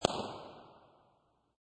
Descarga de Sonidos mp3 Gratis: petardo 1.